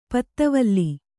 ♪ patra valli